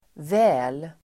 väl [vä:l] LYSSNA adv. nog, troligen, eller hur?